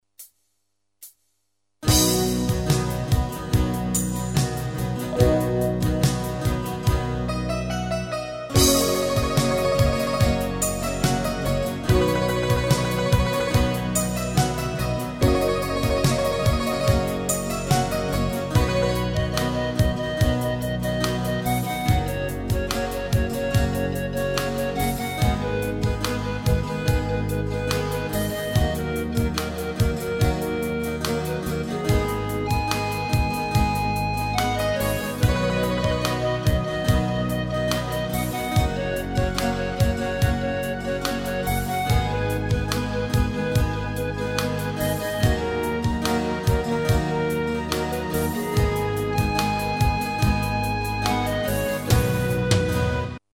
Tempo: 72 BPM.
MP3 with melody DEMO 30s (0.5 MB)zdarma